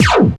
BIG ZAP.wav